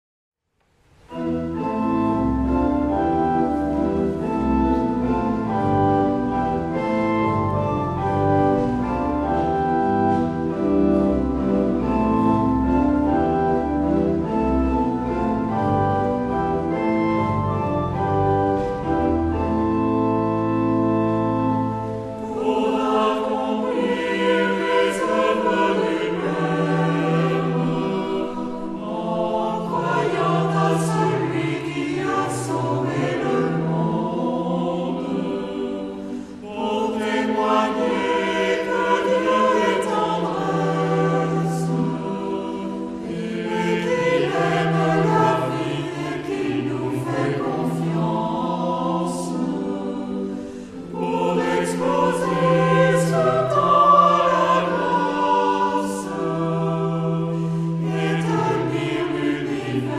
Genre-Style-Forme : Sacré ; Hymne (sacré)
Caractère de la pièce : calme
Type de choeur : SAH  (3 voix mixtes )
Instruments : Orgue (1)
Tonalité : la mineur